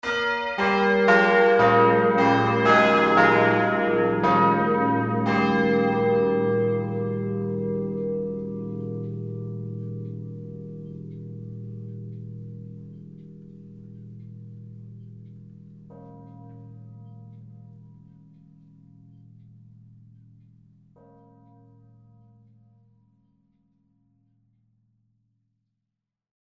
Stadtkirche_Burgkirche_Glocken.mp3